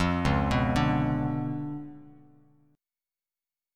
Db7b9 chord